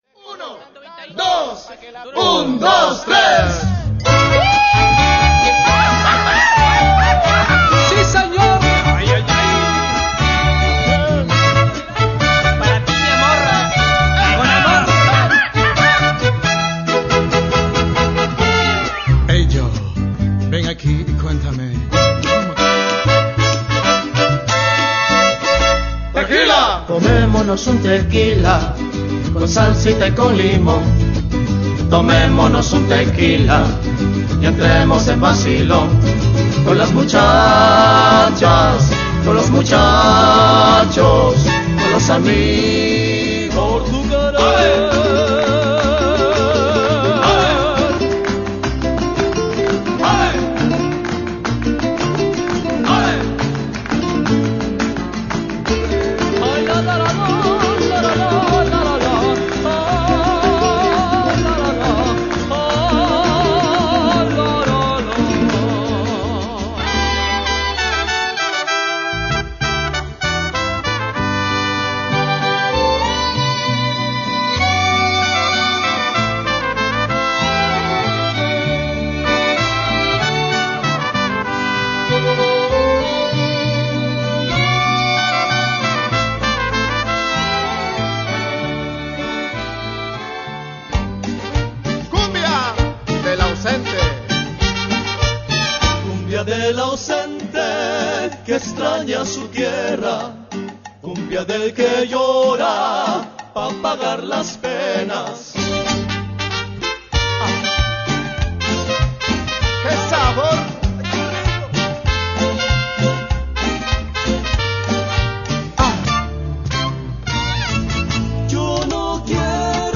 unique and energetic music style